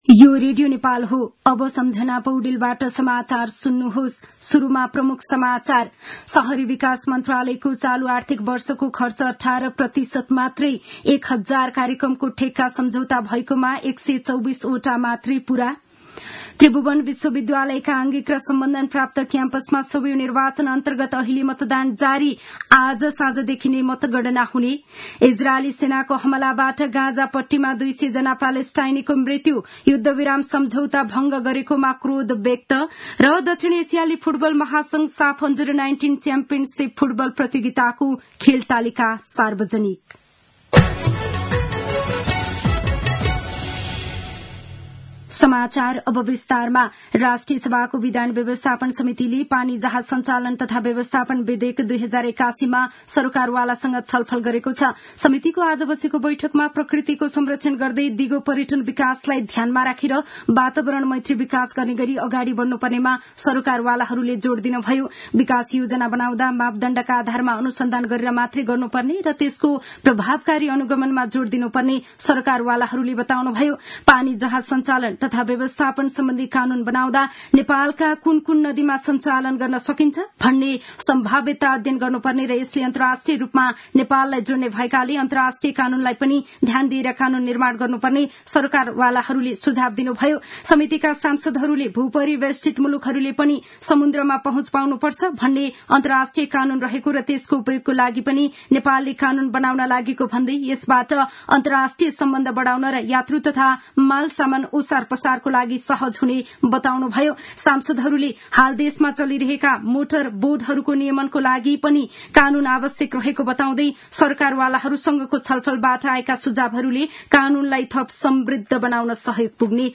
दिउँसो ३ बजेको नेपाली समाचार : ५ चैत , २०८१
3-pm-news-1-5.mp3